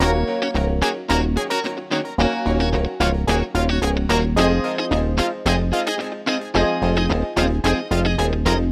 03 Backing PT1.wav